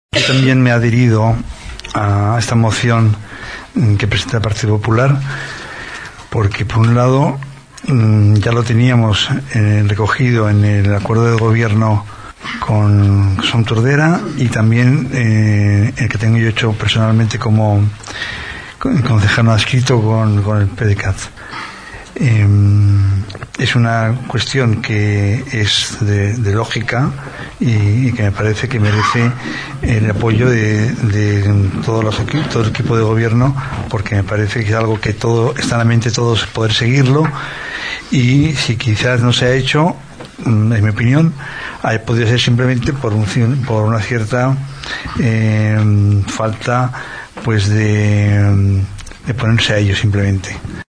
Jose Carlos Villaro, actualment regidor no adscrit del govern municipal, també va votar favorablement a la moció.